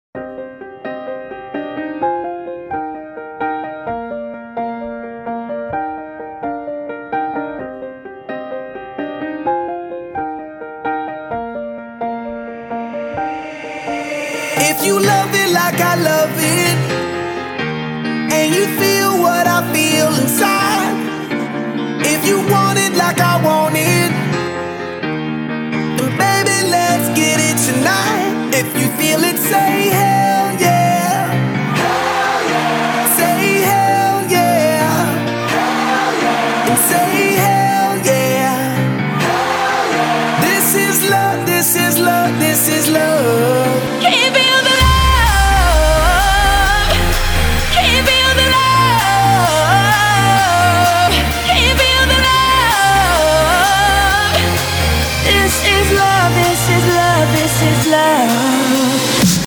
• Качество: 256, Stereo
красивые
клавишные
пианино